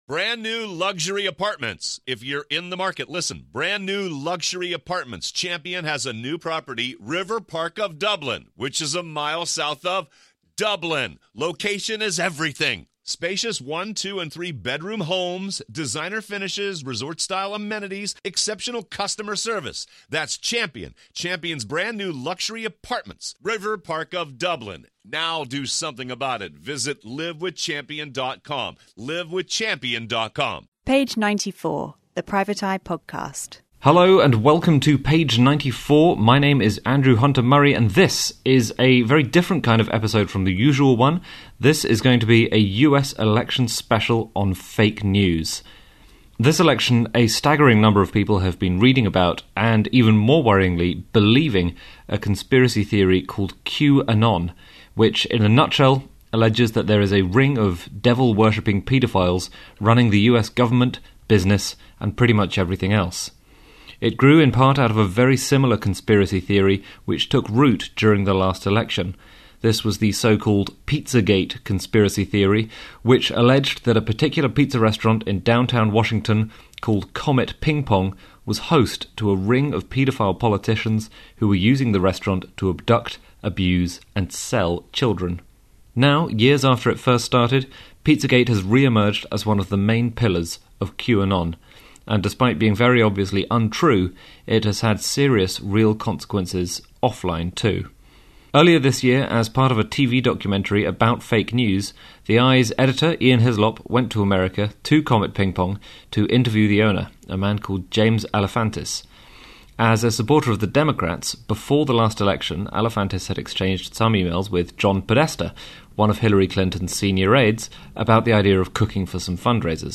56: US Election Fake News Special Page 94: The Private Eye Podcast Page 94: The Private Eye Podcast News 4.6 • 1.6K Ratings 🗓 31 October 2020 ⏱ 29 minutes 🔗 Recording | iTunes | RSS 🧾 Download transcript Summary Episode 56.